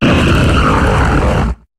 Cri de Regirock dans Pokémon HOME.